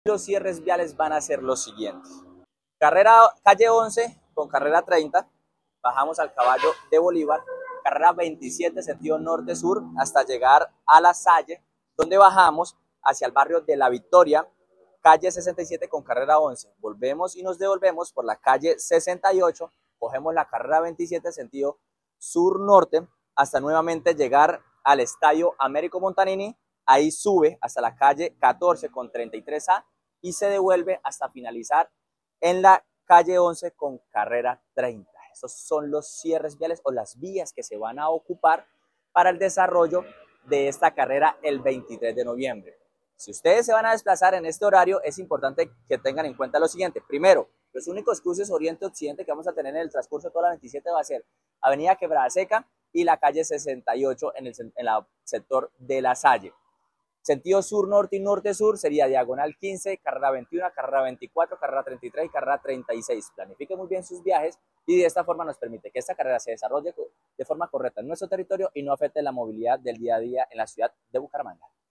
Jhair Manrique, director de Tránsito Bucaramanga